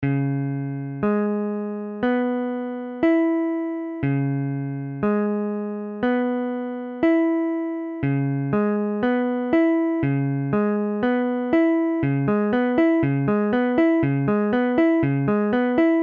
Dbm7 : accord de R� b�mol mineur septi�me Mesure : 4/4
Tempo : 1/4=60
IV_Dbm7.mp3